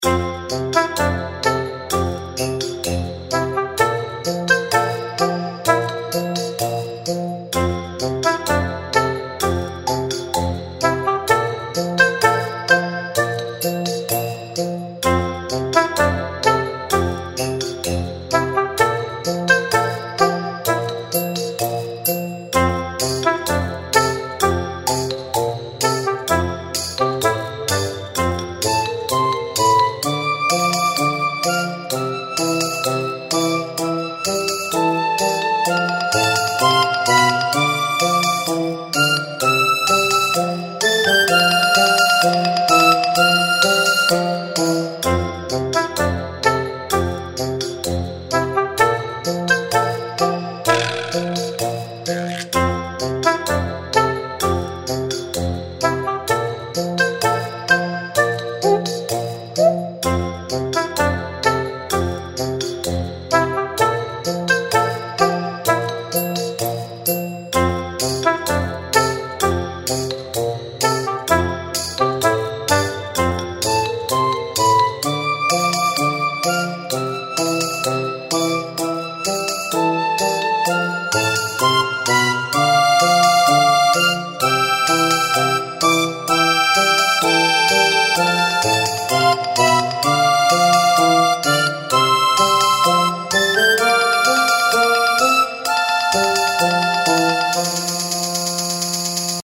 明るい音色の中に少しの戸惑いや慎重さが感じられ「挑戦」というテーマにぴったりの空気感を漂わせています。
そんな風景が、優しいメロディと穏やかなハーモニーの中に浮かびます。
• ステレオ音源、サンプリングレート：44.1kHz
• 主なピーク周波数： 約175Hz（温かみのある中低域が中心）
• 構成：Aメロ～Bメロと続き、ラストで穏やかに終息するワンループ構成
• 楽器編成（推定）：マリンバやベル系の可愛らしい音色を中心にオーボエ、ピッコロ、軽やかなリズム楽器がサポート。
初心者や子ども向けの世界観を壊さないやさしいサウンド設計が特徴です。